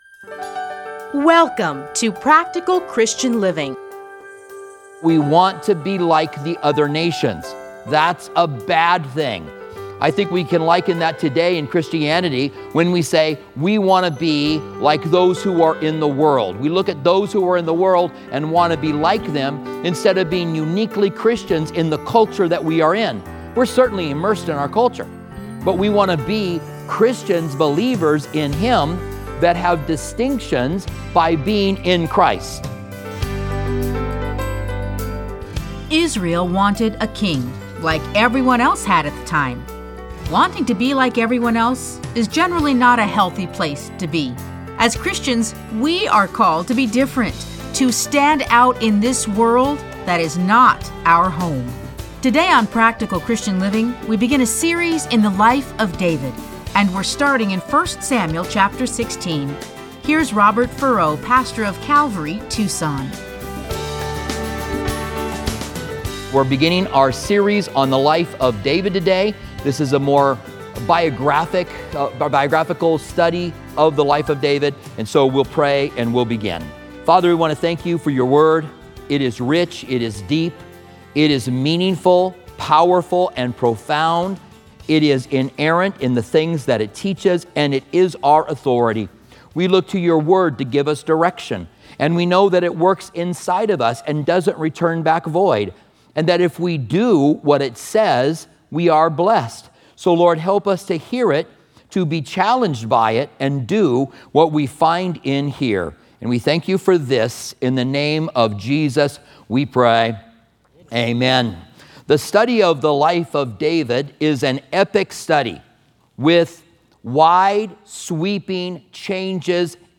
Listen to a teaching from 1 Samuel 16:1-13.